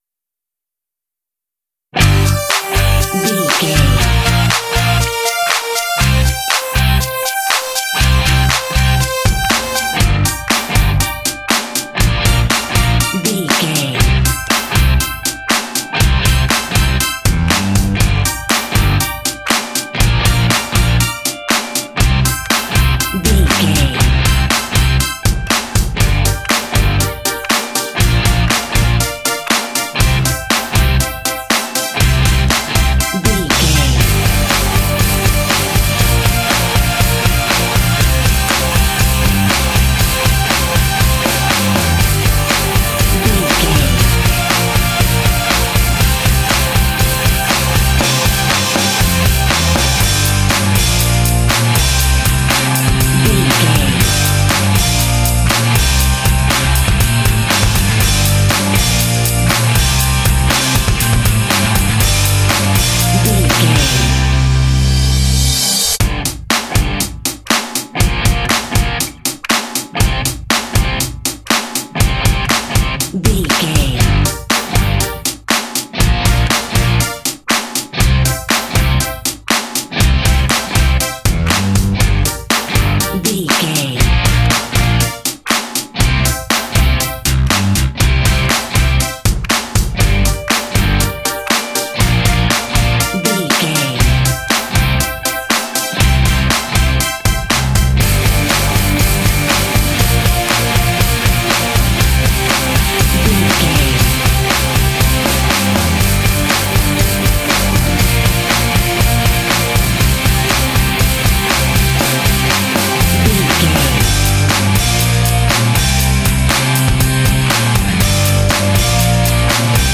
Aeolian/Minor
powerful
energetic
synthesiser
electric guitar
bass guitar
drums
heavy metal
hard rock